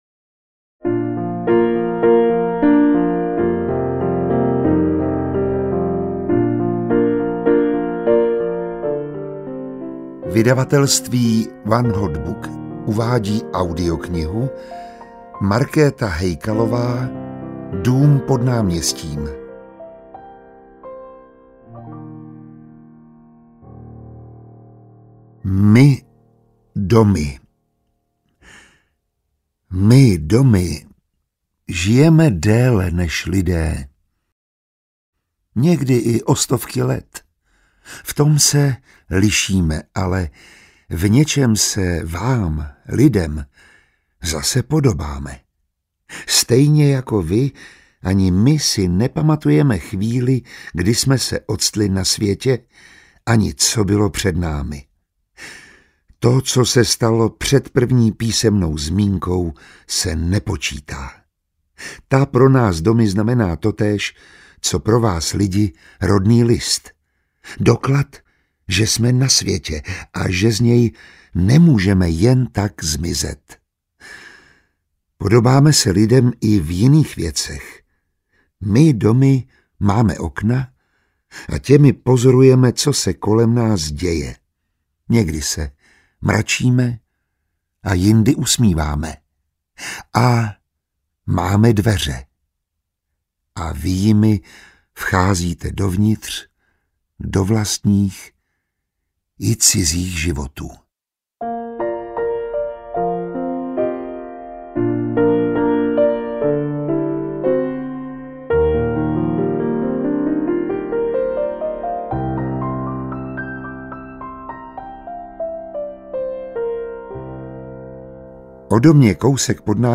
Dům pod náměstím audiokniha
Ukázka z knihy